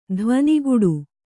♪ dhvaniguḍu